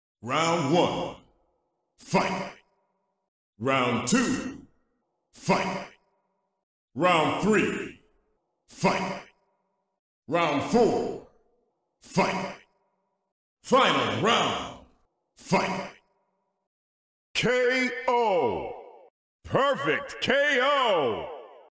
Thể loại: Đánh nhau, vũ khí
Description: Tổng hợp hiệu ứng âm thanh Chiến đấu, bắt đầu cuộc thi, bắt đầu trò chơi trong Street Fighter 6 (theo từng hiệp) + Hạ gục (KO) + Hạ gục Hoàn hảo (Perfect KO)/ Street Fighter 6 Fight (with rounds) + KO + Perfect KO... sound effects... Âm thanh này thường nghe thấy trong game hoặc cuộc thi trả lời câu hỏi.
am-thanh-chien-dau-street-fighter-6-theo-tung-hiep-ha-guc-ko-perfect-ko-www_tiengdong_com.mp3